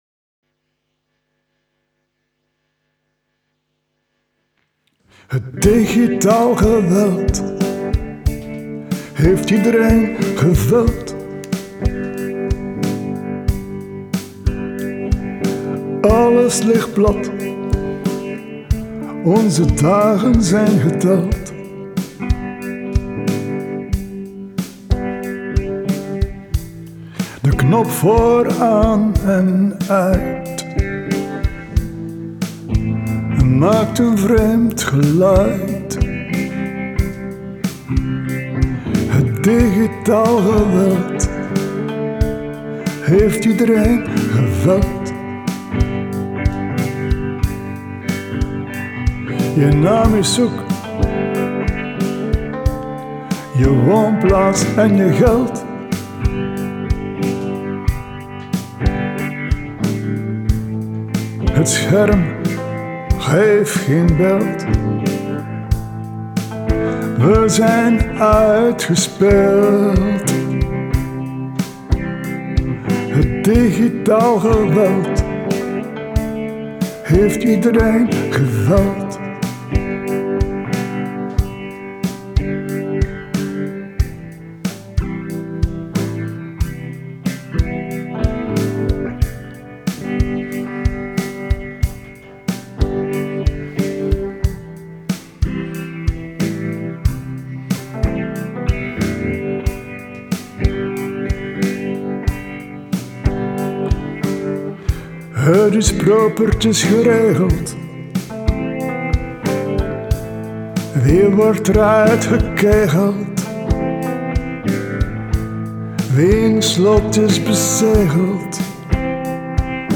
Hier vind ik m’n draai niet … een vertelsel op muziek?